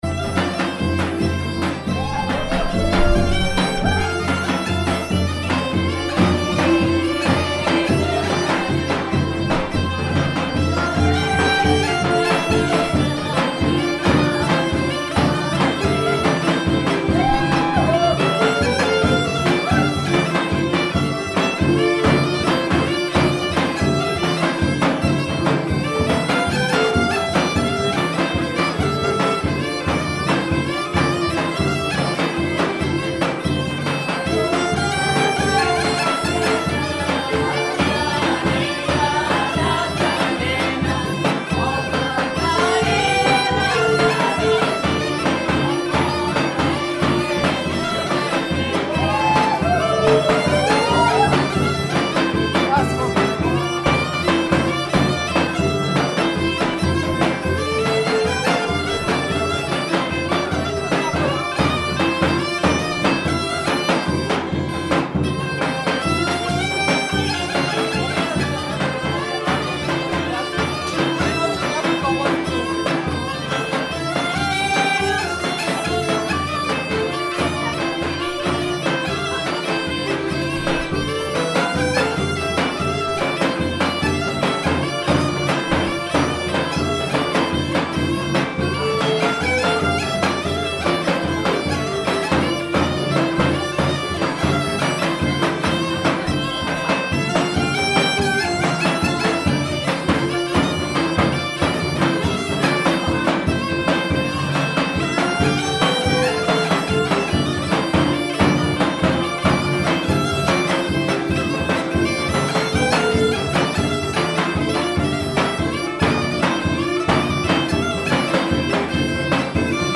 Momi Mari field recording from village of Dulboki Bulgaria August 2010.mp3